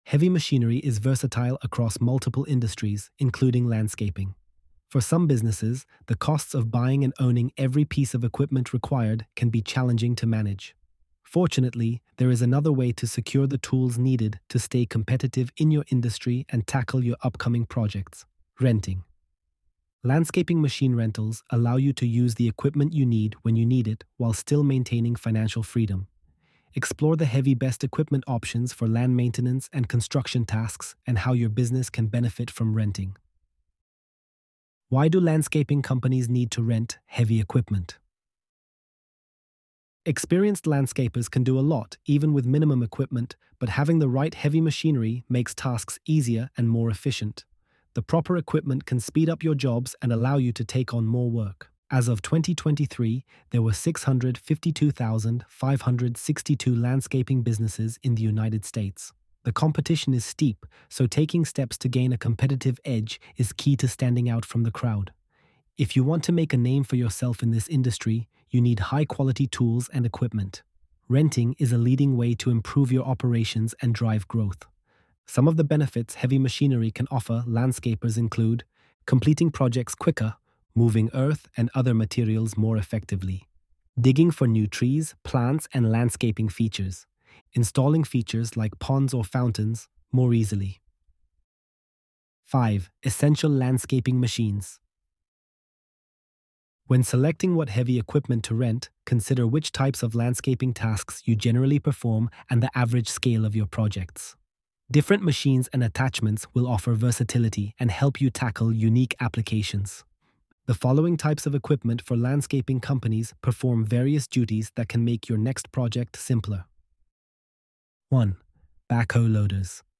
ElevenLabs_Chapter_11.mp3